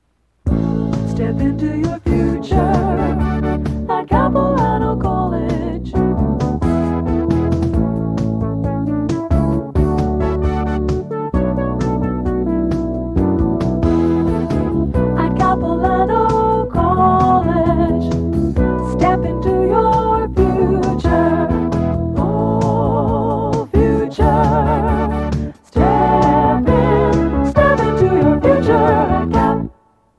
Promotional Audio/Radio Jingle
audio cassette